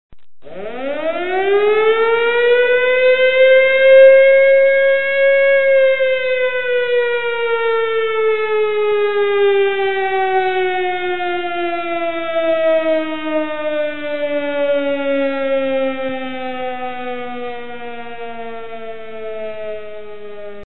Sirena_.mp3